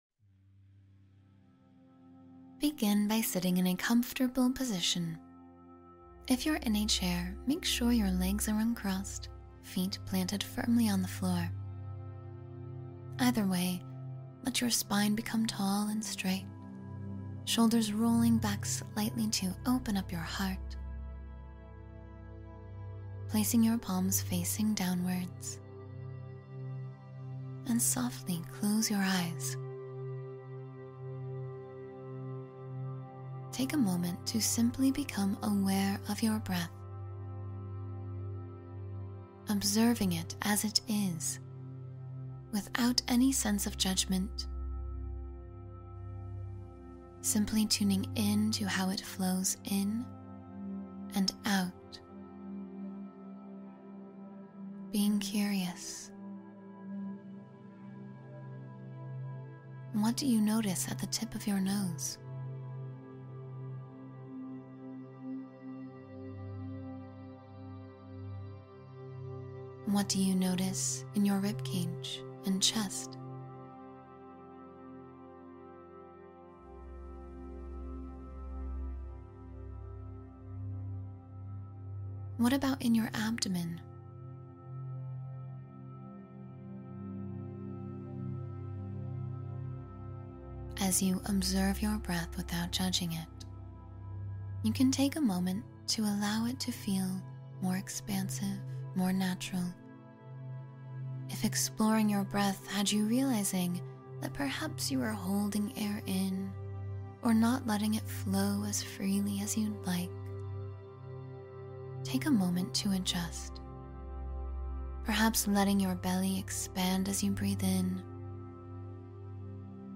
10-Minute Mindfulness Meditation for Total Presence and Clarity